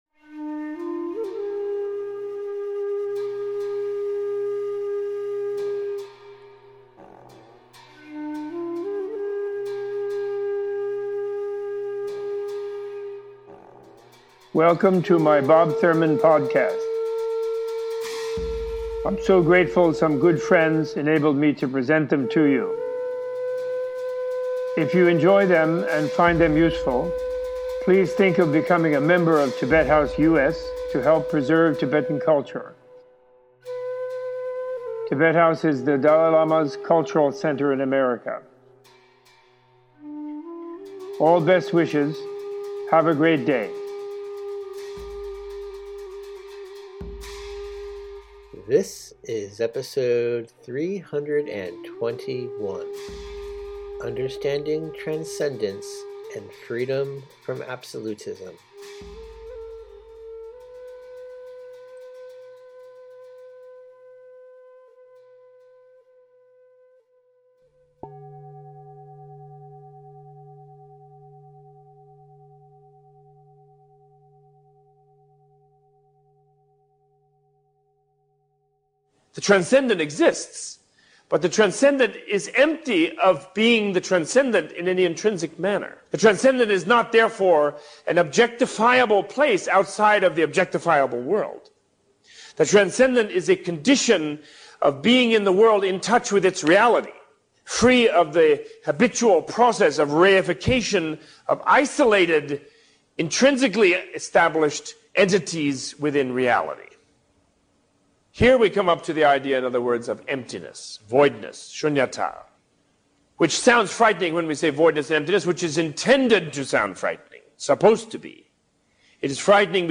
Opening with a discussion of transcendence in Buddhist science and philosophy, Robert Thurman gives a teaching on selflessness, transcendent wisdom, and the key of how to develop freedom from absolutism in order to cultivate altruism and interconnection with all beings and reality. This podcast includes a discussion on how understanding selflessness leads beyond space-like equipoise samadhi to natural focus on universal love and compassion, intensifying the desire to be of benefit to oneself and